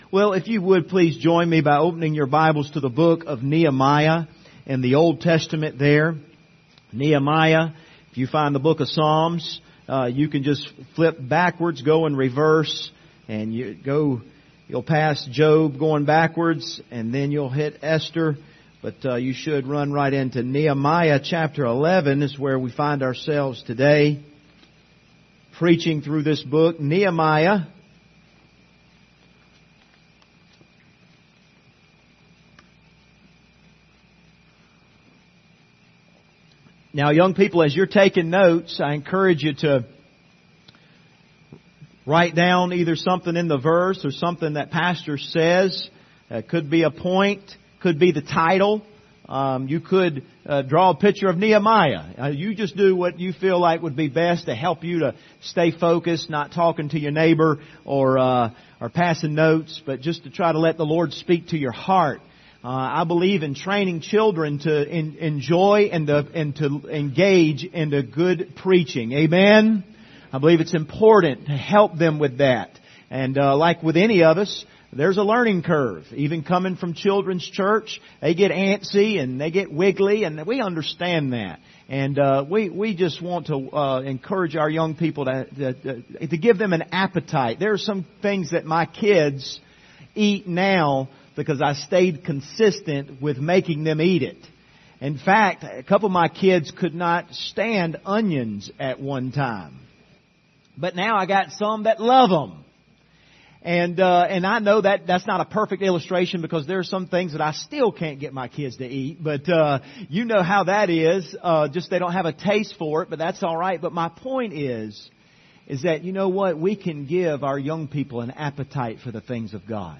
Passage: Nehemiah 11 Service Type: Sunday Morning